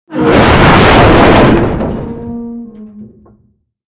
collision_shipasteroid2.wav